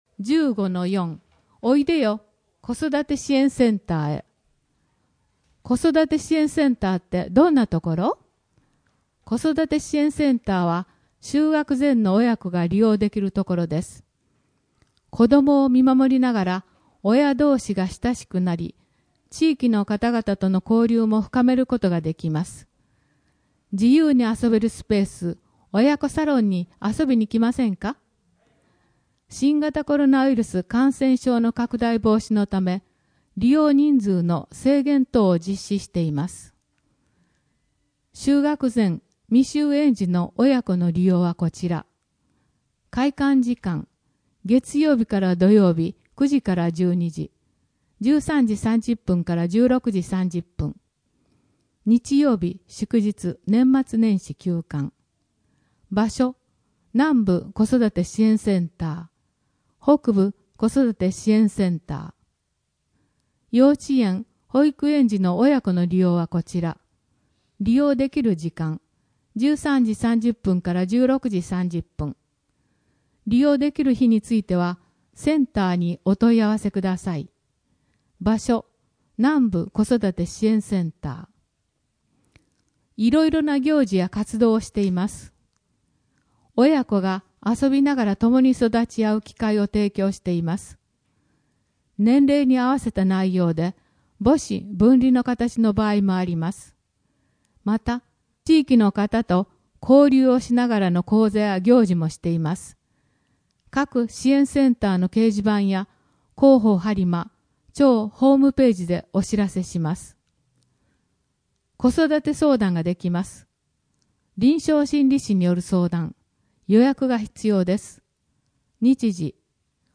声の「広報はりま」6月号
声の「広報はりま」はボランティアグループ「のぎく」のご協力により作成されています。